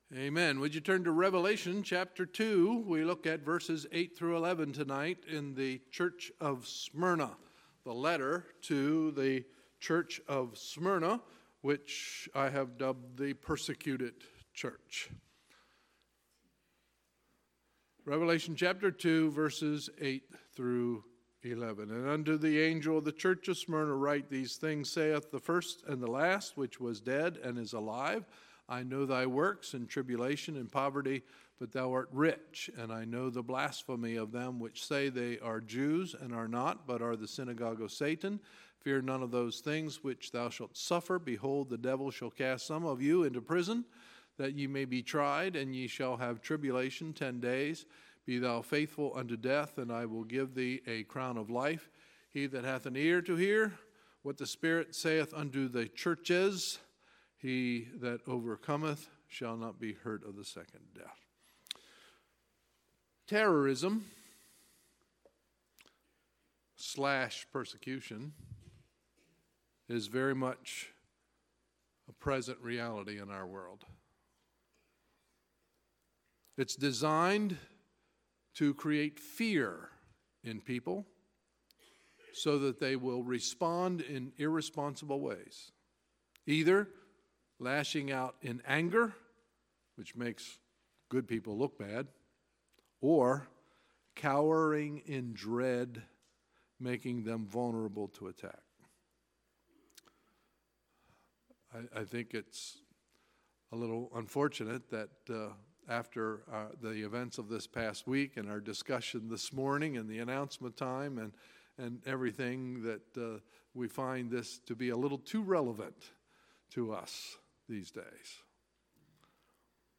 Sunday, February 25, 2018 – Sunday Evening Service